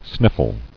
[snif·fle]